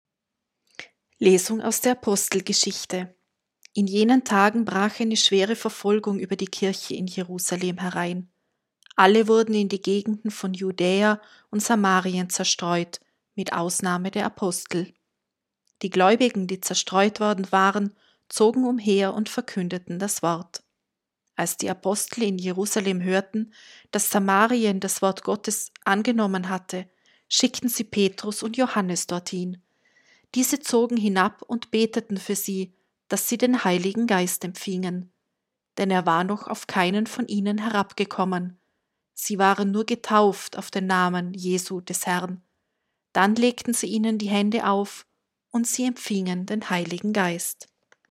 1.-Lesung-Pfingstmontag.mp3